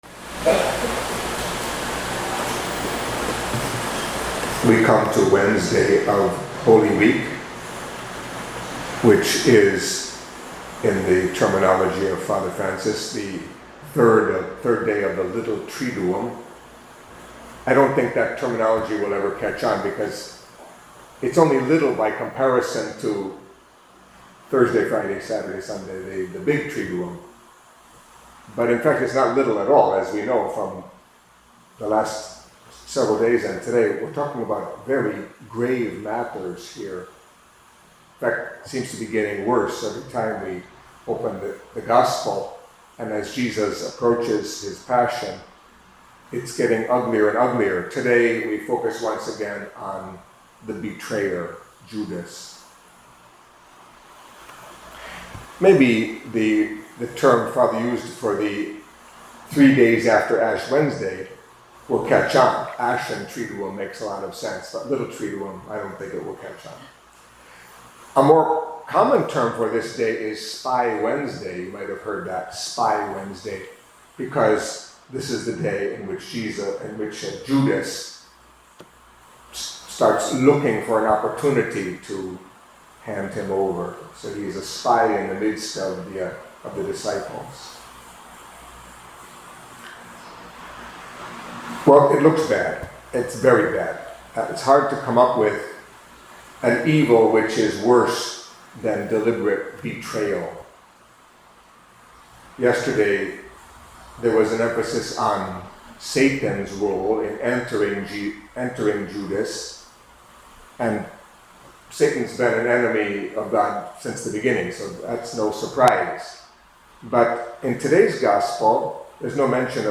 Catholic Mass homily for Wednesday of Holy Week